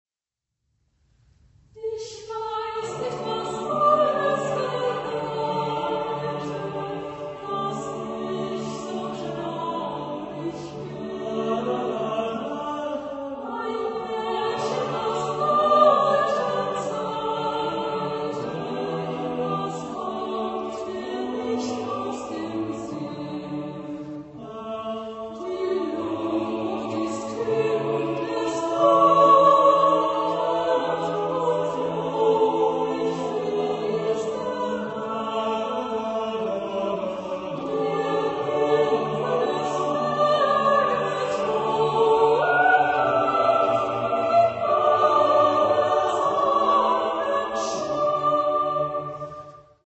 Genre-Stil-Form: Chorbearbeitung ; Volkslied ; weltlich
Chorgattung: SATB  (4 gemischter Chor Stimmen )
Tonart(en): D-Dur